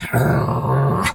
pgs/Assets/Audio/Animal_Impersonations/dog_2_growl_04.wav at master
dog_2_growl_04.wav